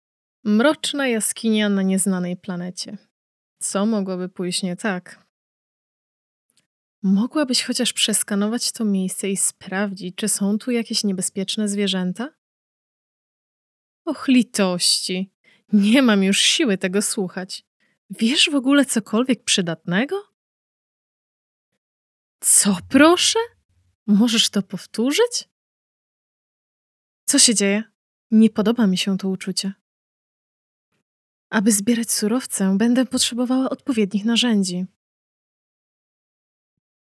POLISH GAME VOICE OVER
Honeycomb- The World Beyond - Główna bohaterka (main character VO).mp3